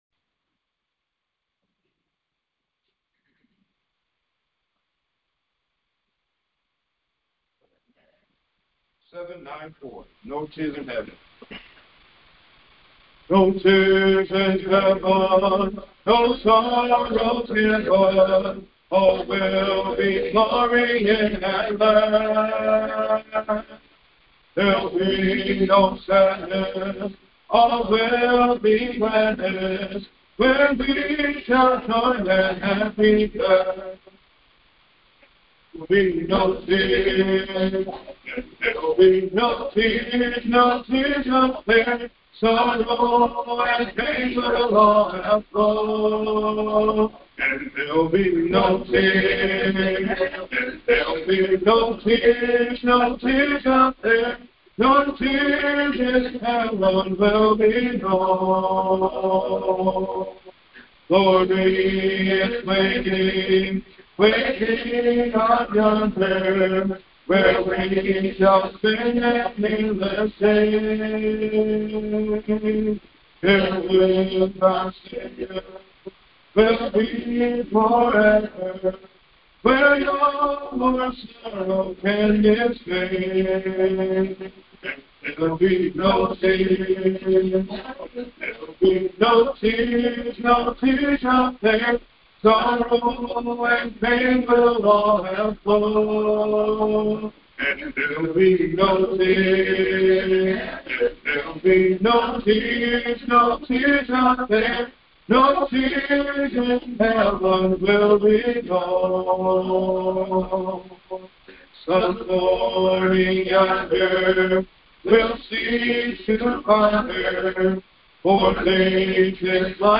Sunday Evening Service 1.11.25